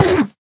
minecraft / sounds / mob / endermen / hit2.ogg
hit2.ogg